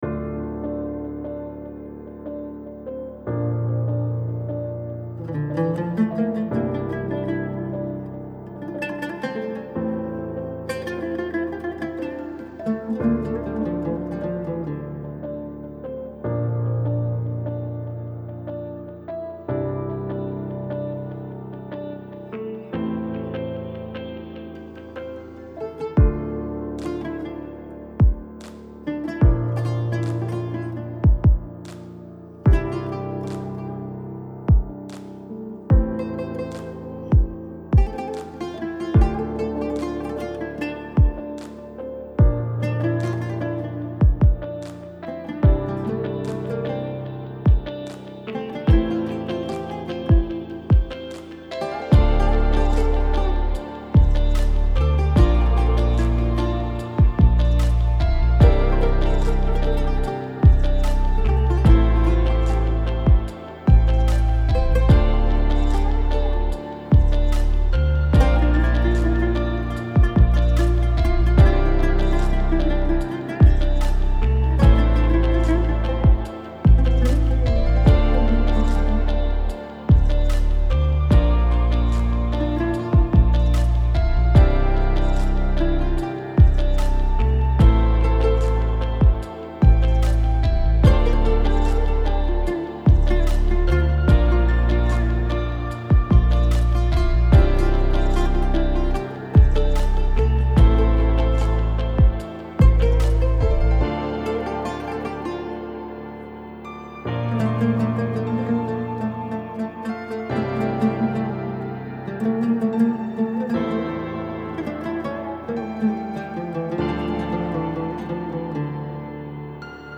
Audio Branding Elements
Music Theme Loop